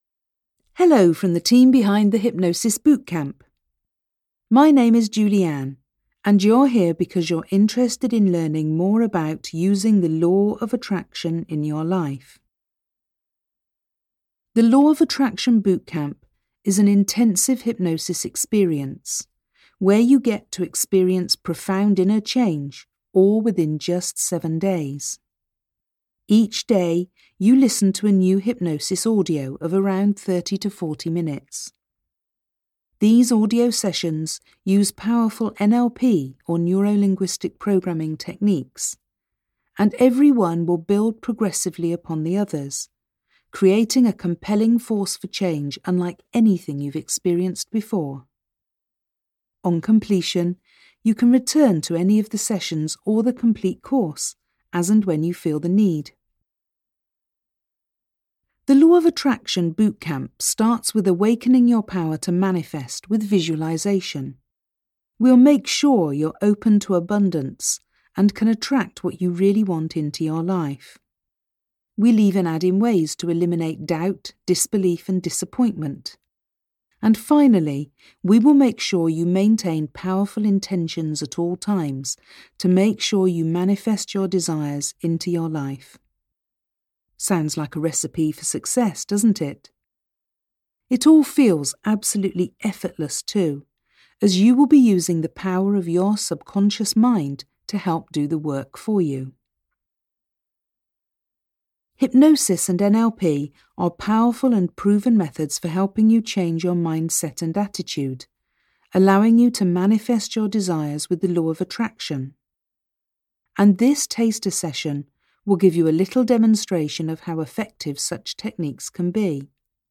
Free Guided Meditations Thank You For Being A Part Of Our Community!